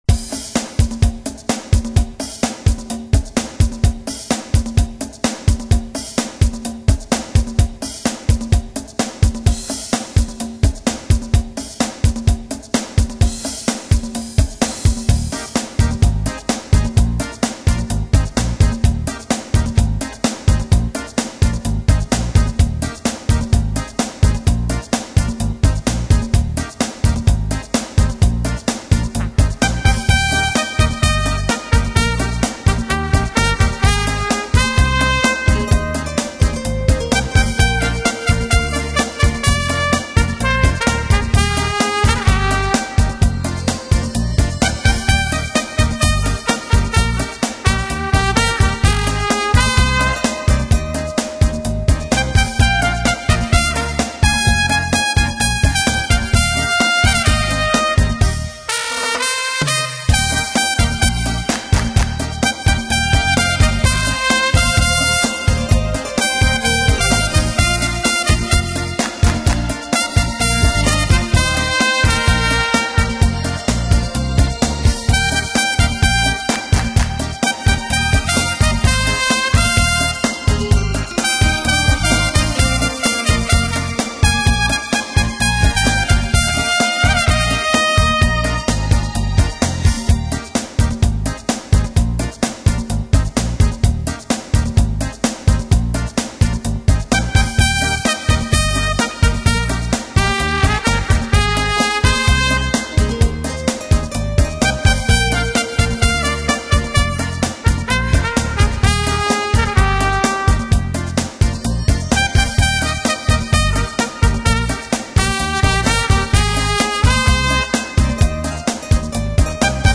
【其它小号专辑链接】